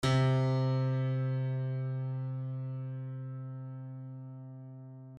piano-sounds-dev
c2.mp3